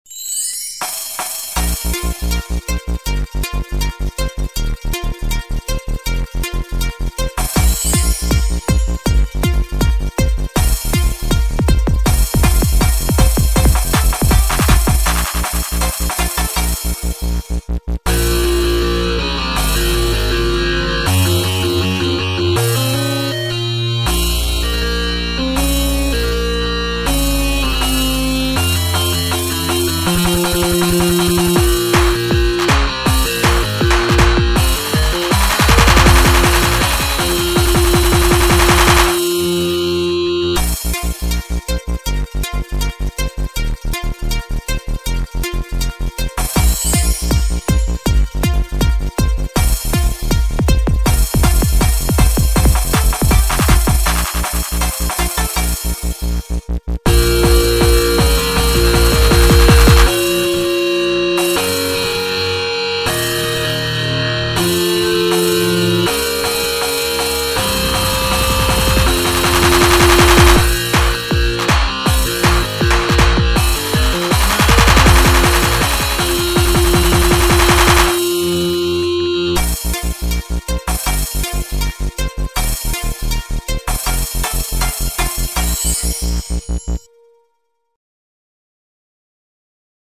An instrumental recording.
She had so much fun creating this one, playing around with the electric guitar loops on FL Studio.